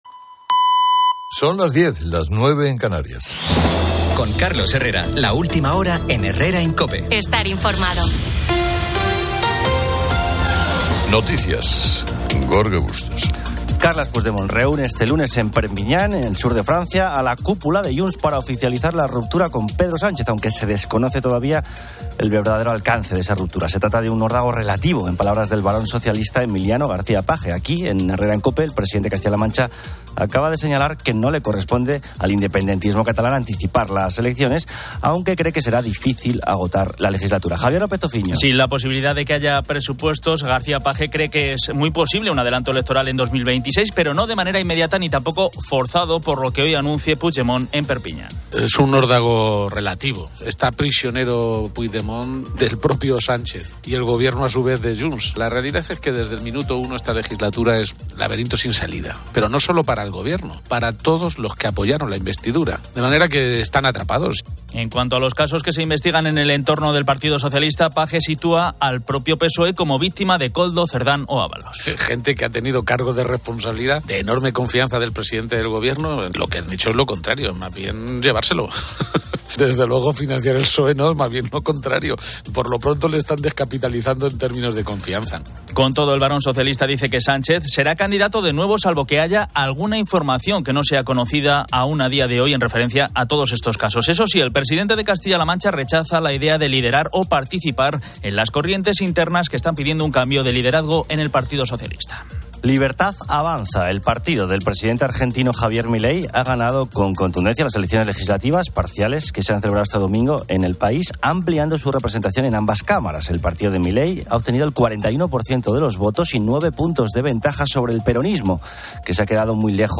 En la sección "La Hora de los Fósforos" en HERRERA EN COPE, se debate sobre la sobreestimación de capacidades, con oyentes que comparten experiencias personales de motivación excesiva, como nadar 5 km en mar abierto o la primera cirugía de un médico.